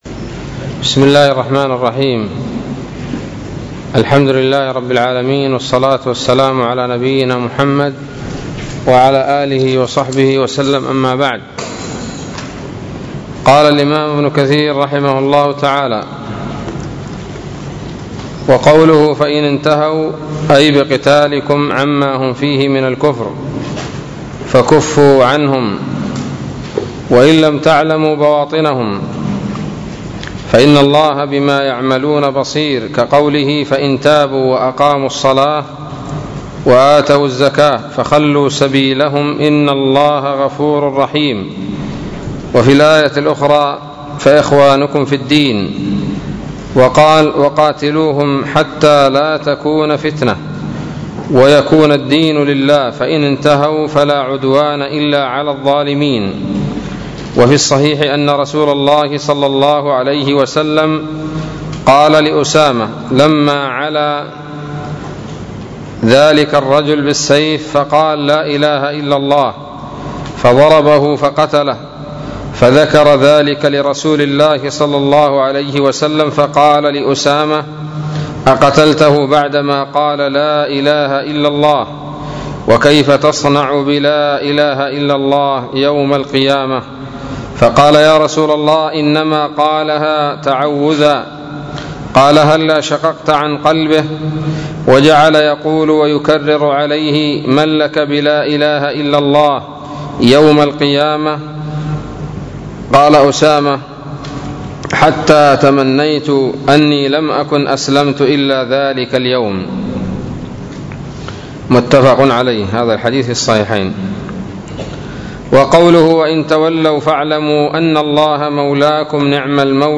الدرس الثاني والعشرون من سورة الأنفال من تفسير ابن كثير رحمه الله تعالى